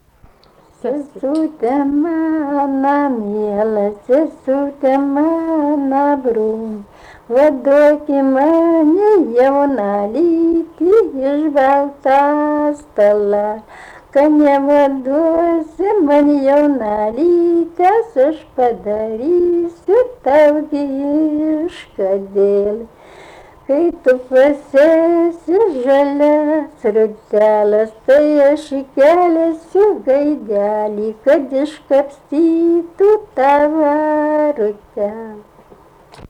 rauda